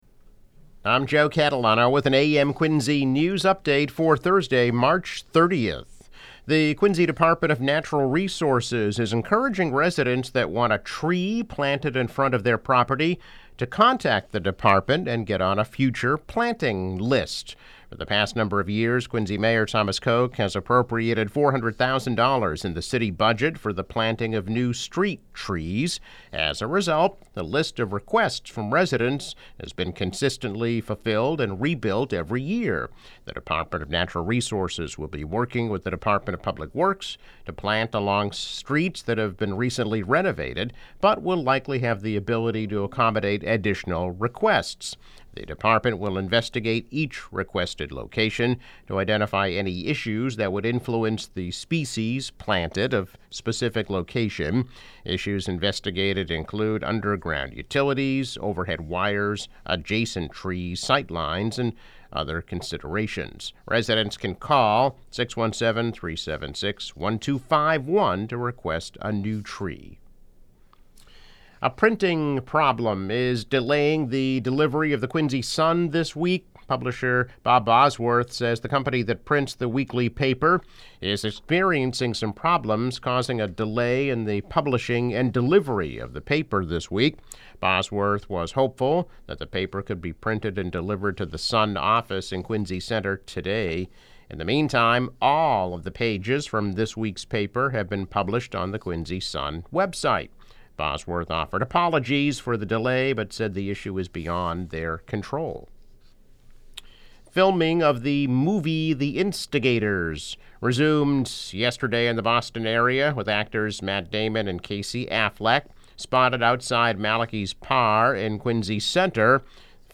Daily news, weather and sports update.